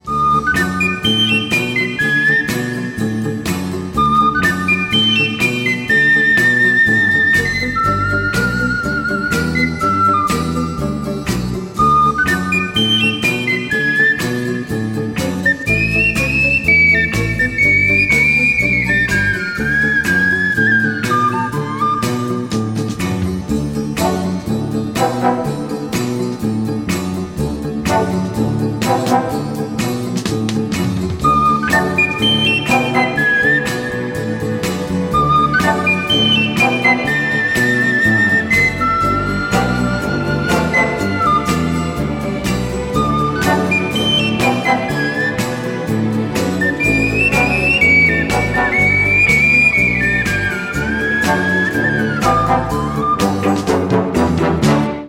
веселые
без слов
инструментальные
Флейта